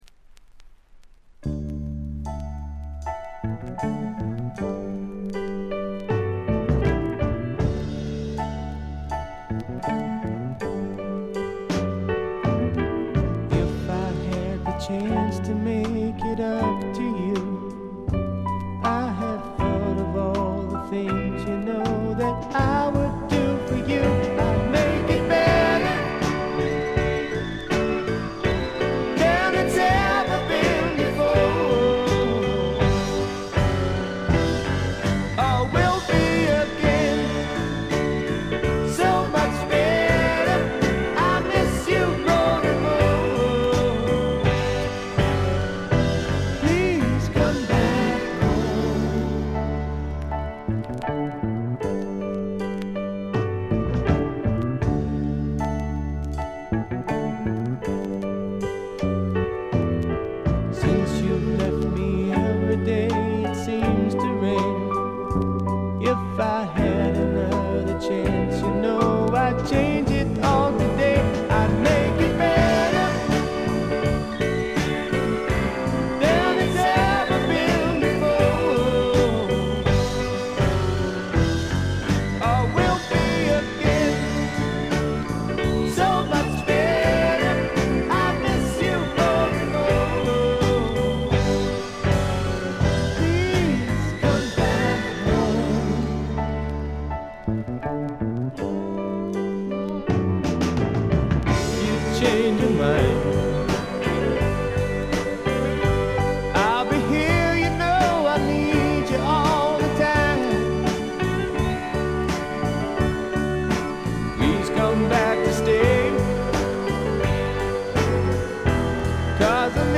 静音部での軽微なチリプチ程度。
試聴曲は現品からの取り込み音源です。